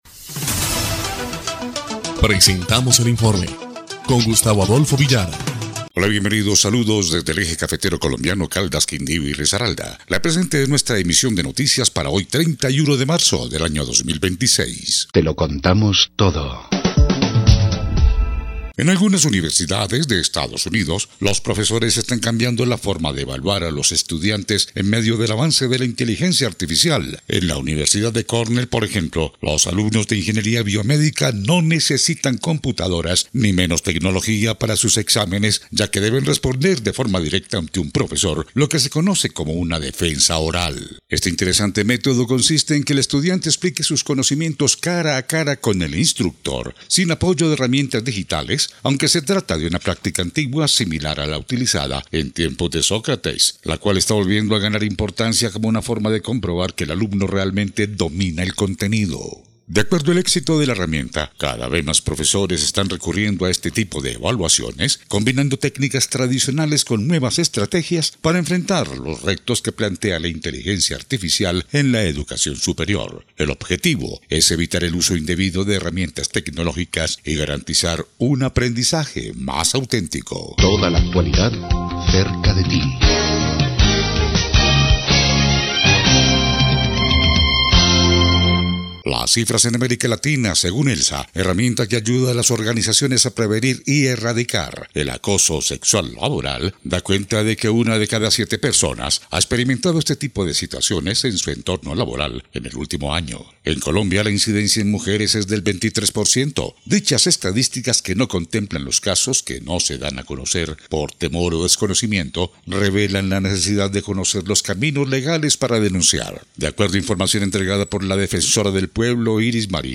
EL INFORME 2° Clip de Noticias del 31 de marzo de 2026